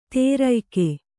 ♪ tērayke